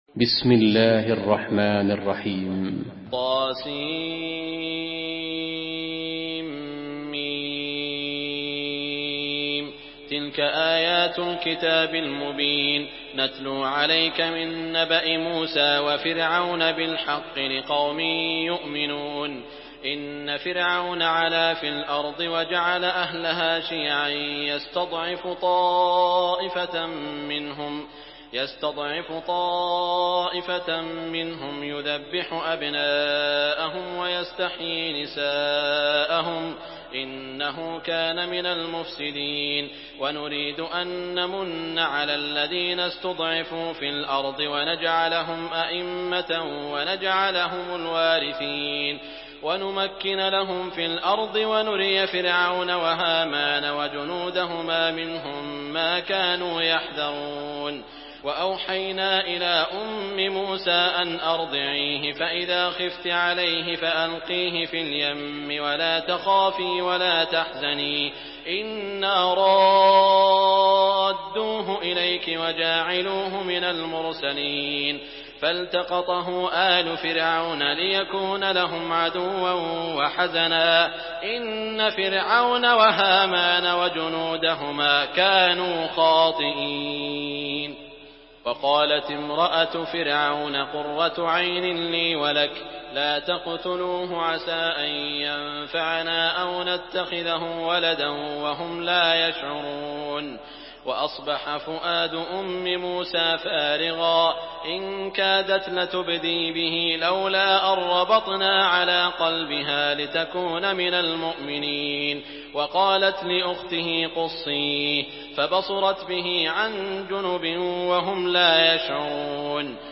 Surah Al-Qasas MP3 by Saud Al Shuraim in Hafs An Asim narration.
Murattal Hafs An Asim